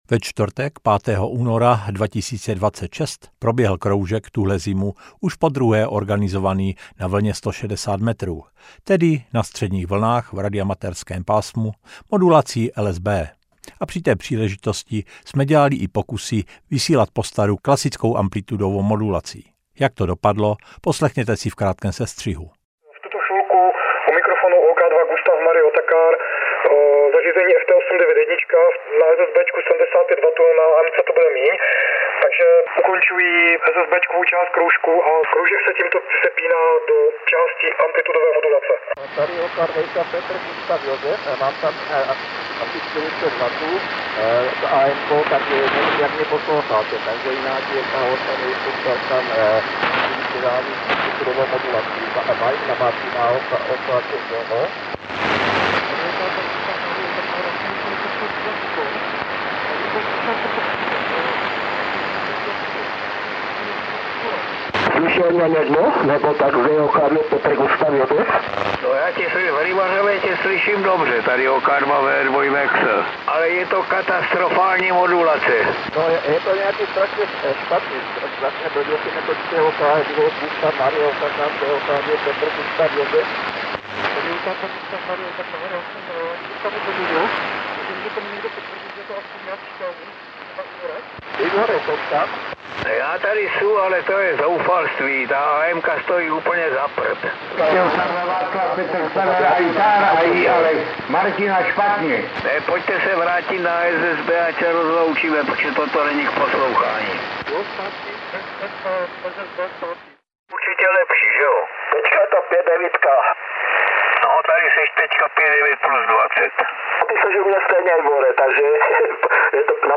Kontrolní nahrávka
Obecně doporučujeme poslech podcastů na stereofonní sluchátka či kvalitní reprosoustavu, protože nenápadné tiché pasáže, zvuky na pozadí nebo prostorové panorama mají v nahrávkách často důležitý význam a jsou jejich nedílnou součástí.